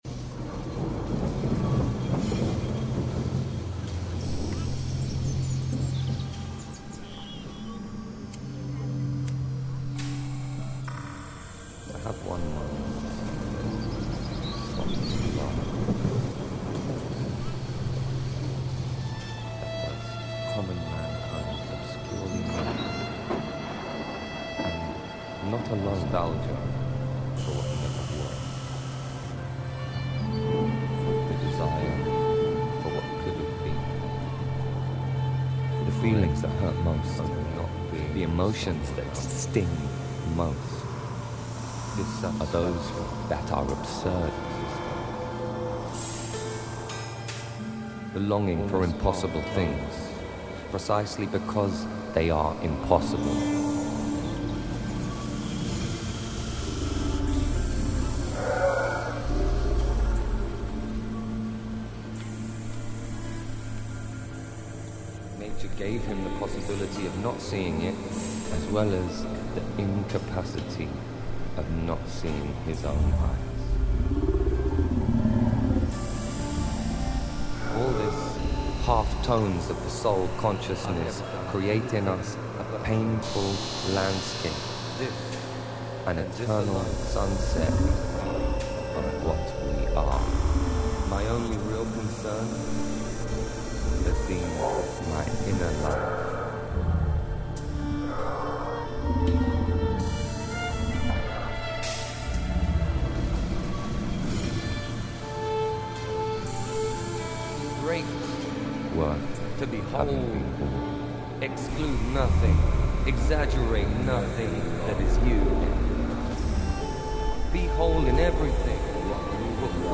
Lisbon trams reimagined